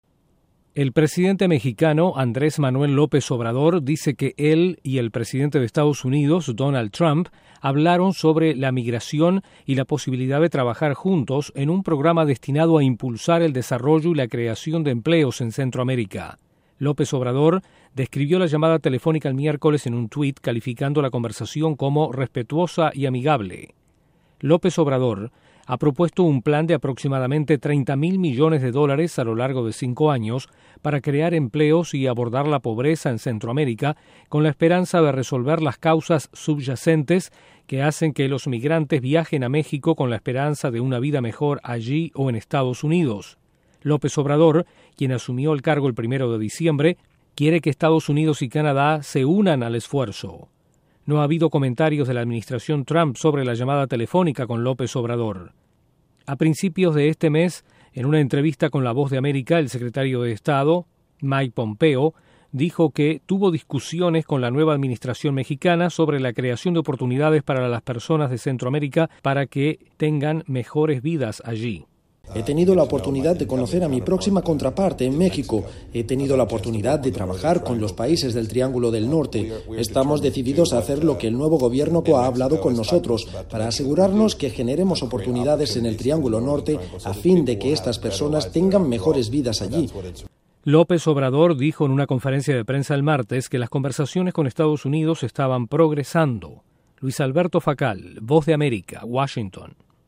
: El presidente de México, Manuel López Obrador, habló por teléfono con su homólogo de EE.UU., Donald Trump, sobre un plan para abordar las causas de la migración centroamericana. Desde la Voz de América en Washington informa